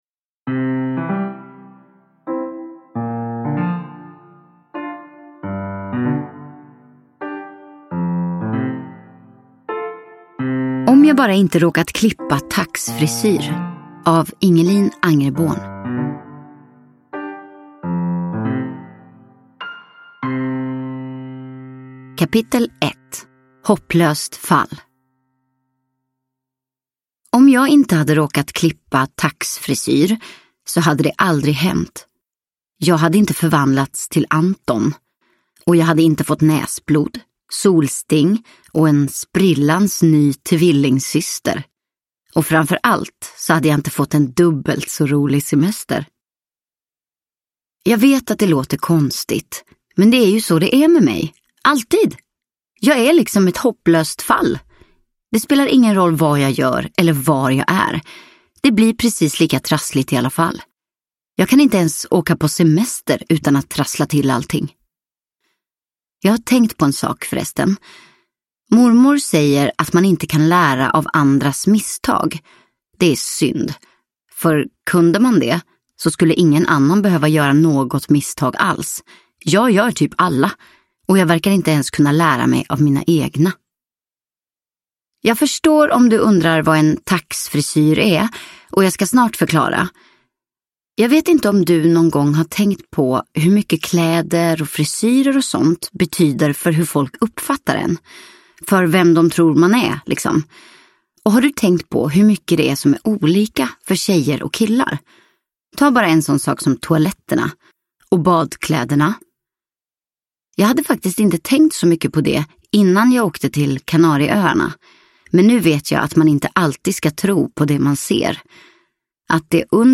Om jag bara inte råkat klippa taxfrisyr – Ljudbok – Laddas ner